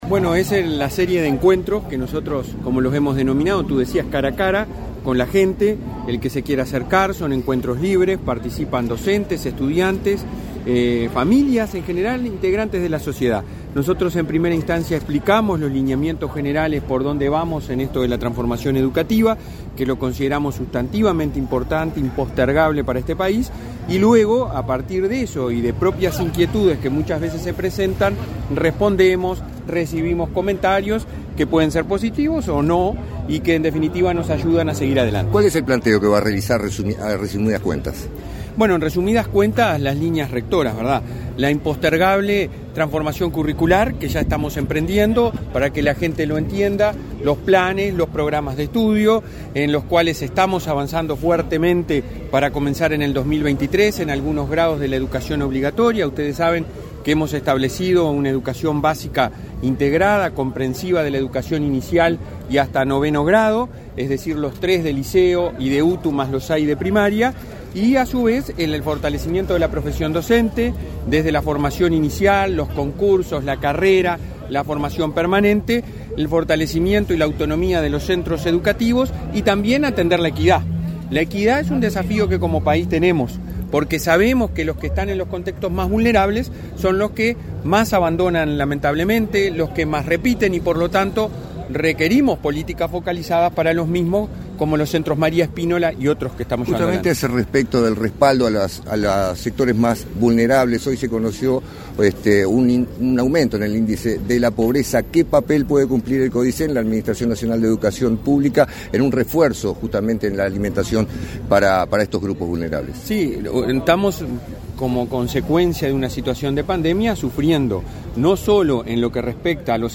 Declaraciones a la prensa del presidente del Codicen de la ANEP, Robert Silva
Tras el evento, el jerarca realizó declaraciones a la prensa.